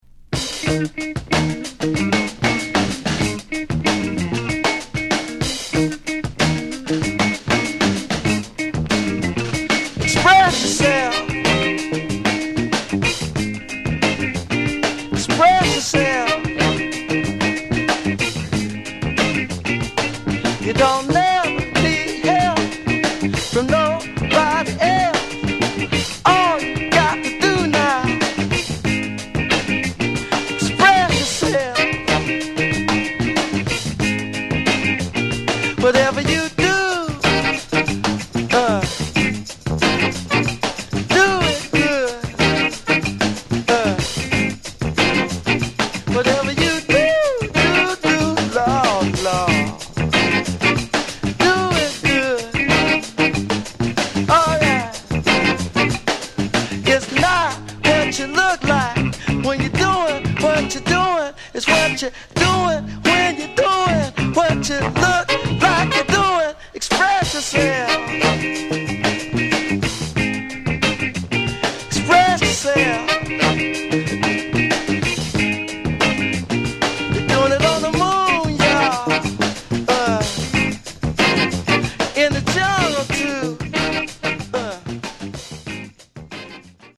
Genre: Funk/Hip-Hop/Go-Go
early Funk